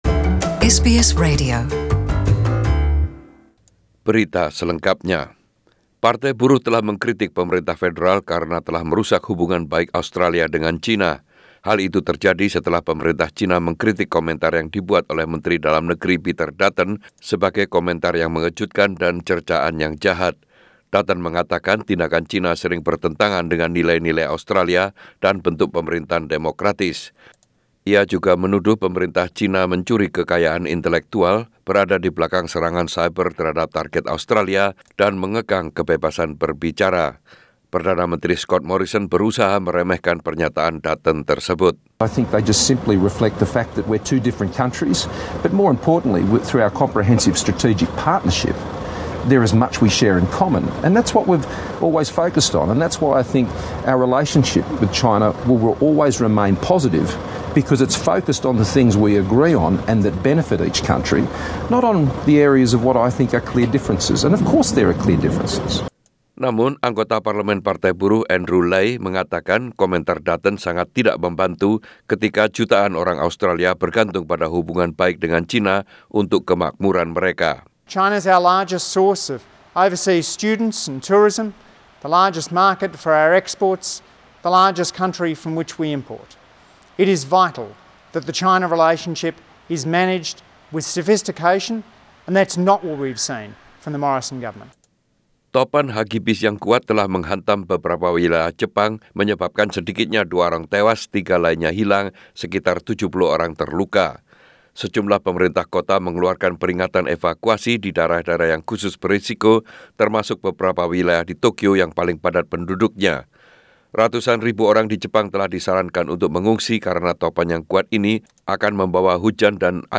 Warta Berita Radio SBS dalam Bahasa Indonesia - 13/10/2019